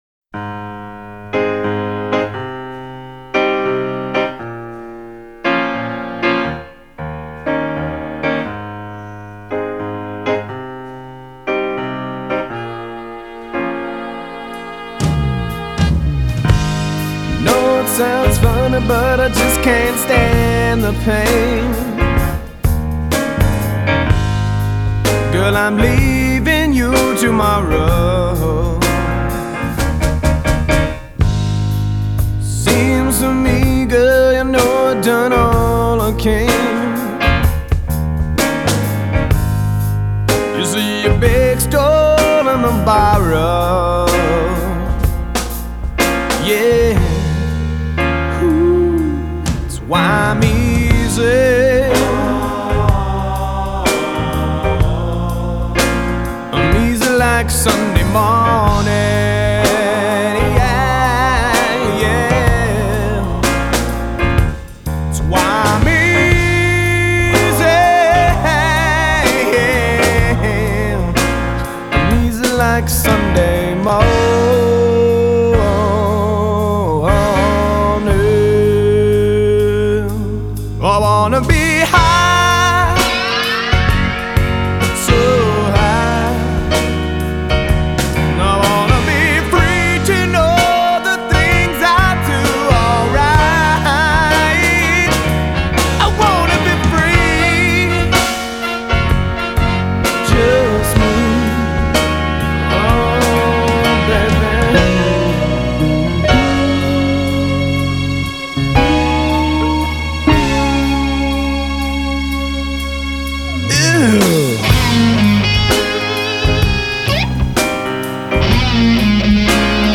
Power Ballad